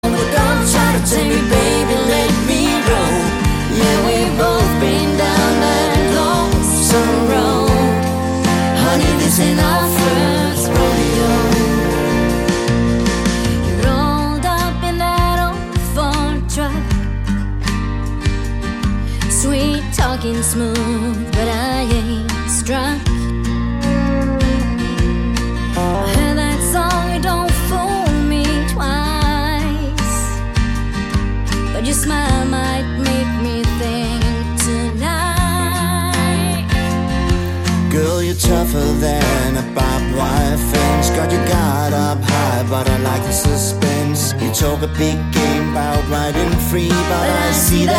Band
der forener det bedste fra countrygenren.